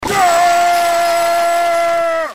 Clash Royale Knight Yelling Soundboard: Play Instant Sound Effect Button